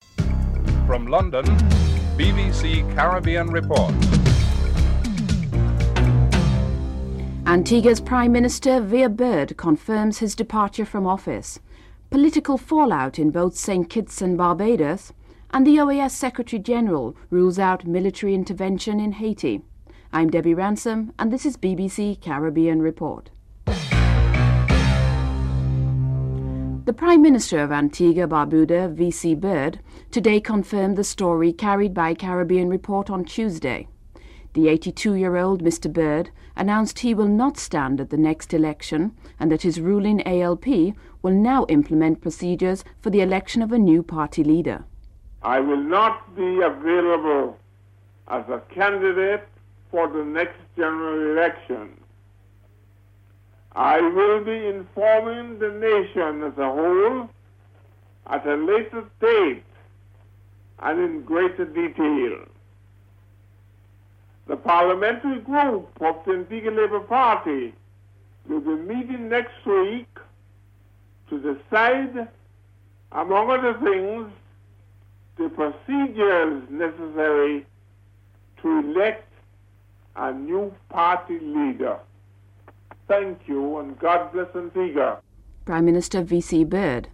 1. Headlines. (00:00-00:26)
OAS Secretary General João Baena Soares responds to questions on the embargo’s effectiveness in an interview (09:22-13:07)